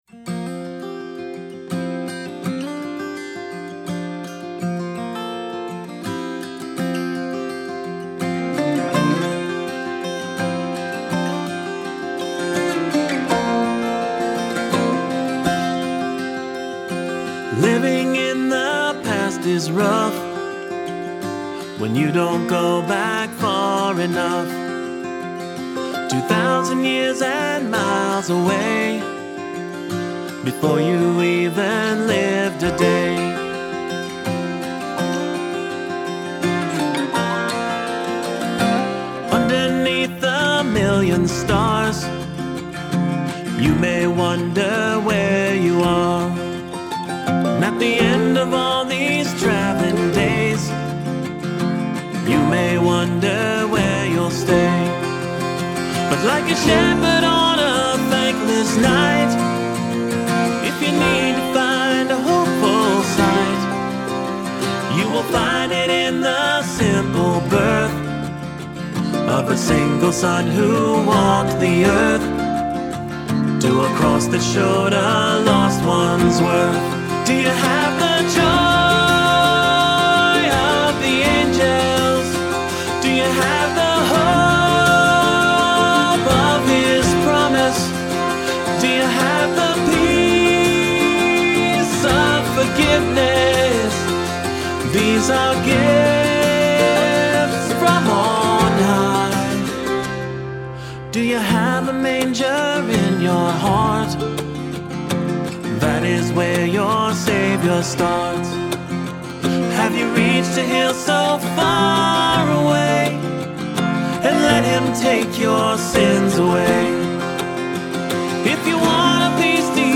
Guitar, Mandolin, Piano, Bouzouki, Backround Vocals
Percussion